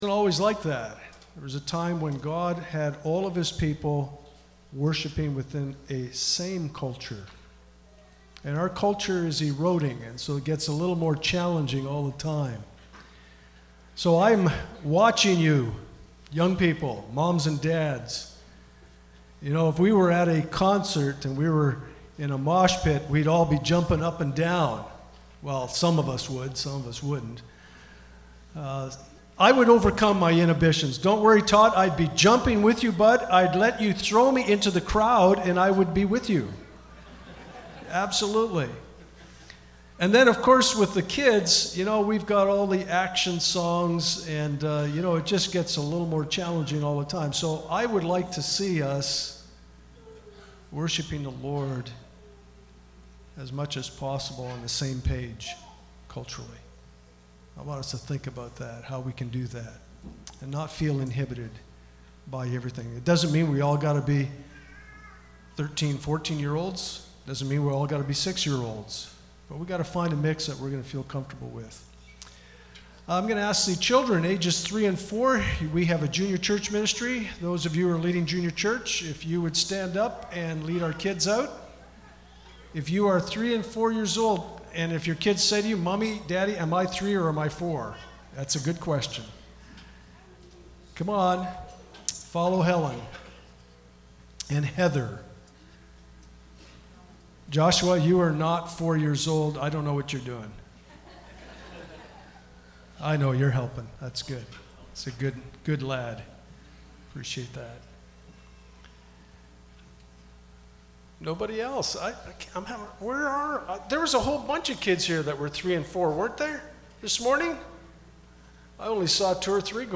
Passage: Philippians 2:12-18 Service Type: Sunday Morning